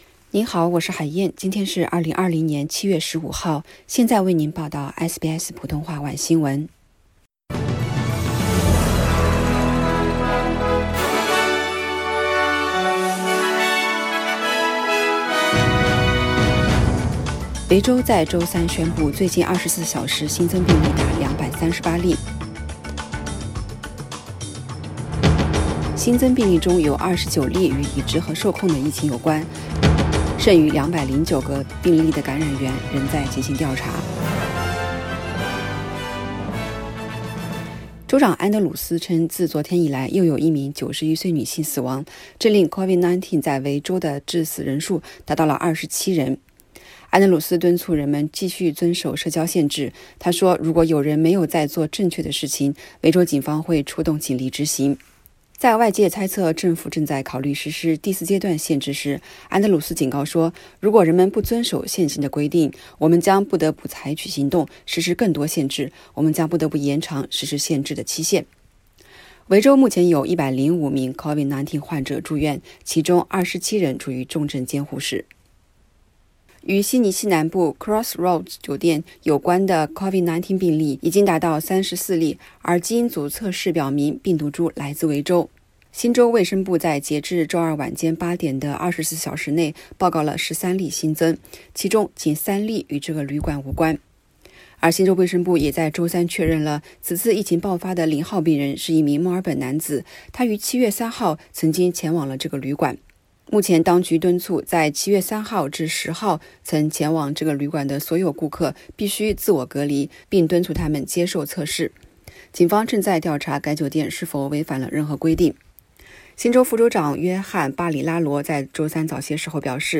SBS晚新闻（7月15日）